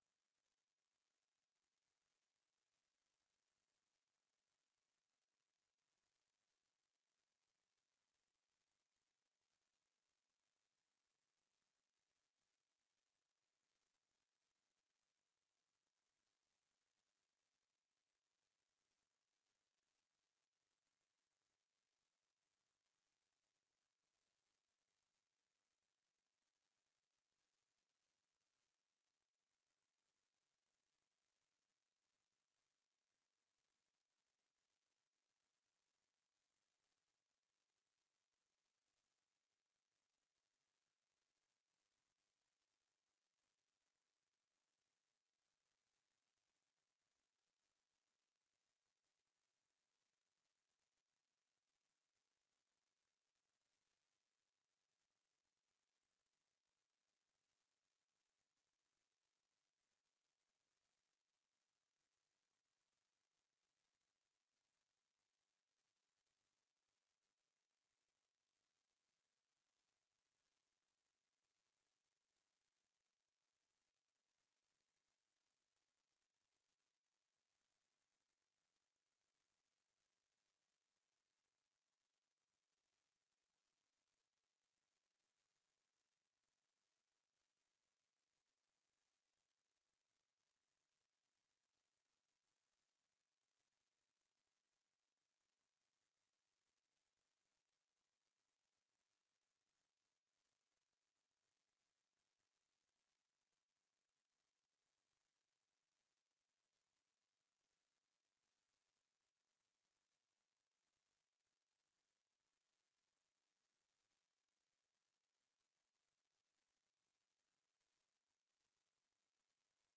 Raadsvergadering 26 september 2024 20:00:00, Gemeente Oude IJsselstreek
Download de volledige audio van deze vergadering